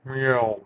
meow10.wav